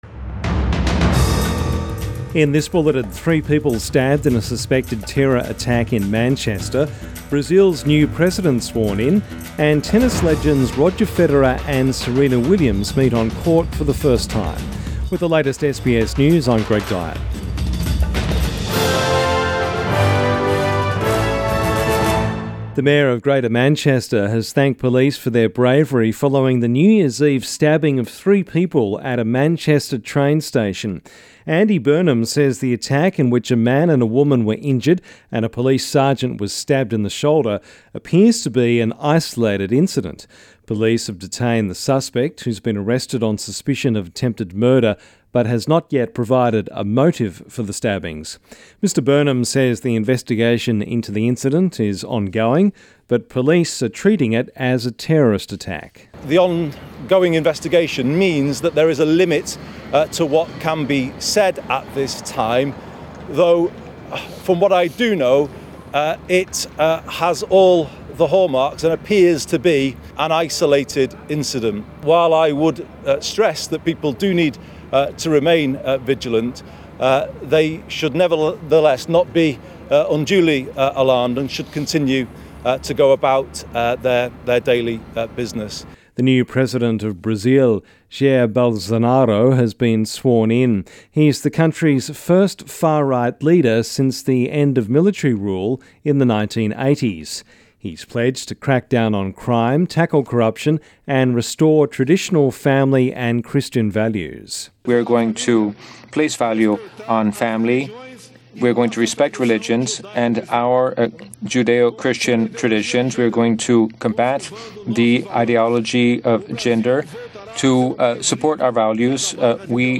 AM bulletin 2 January